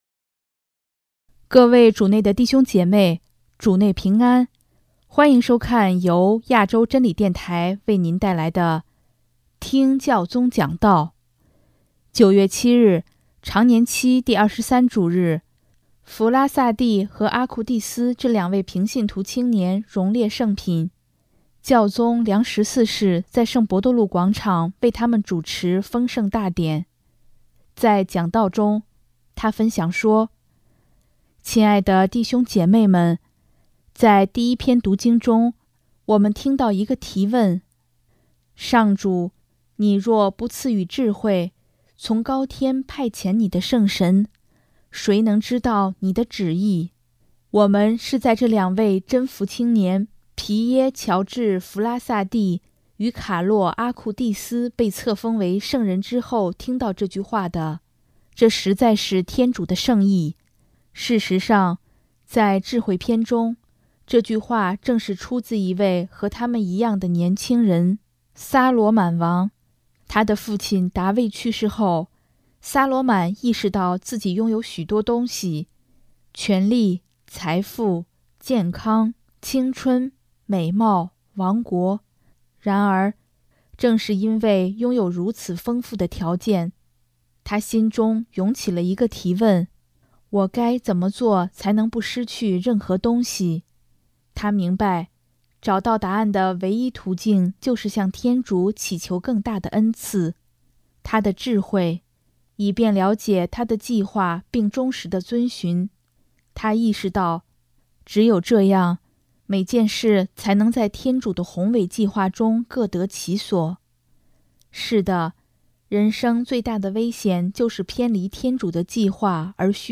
【听教宗讲道】|人生最大的危险就是偏离天主的计划而虚度光阴
9月7日，常年期第二十三主日，弗拉萨蒂和阿库蒂斯这两位平信徒青年荣列圣品，教宗良十四世在圣伯多禄广场为他们主持封圣大典，在讲道中，他分享说：